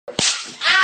Slap! Ahh!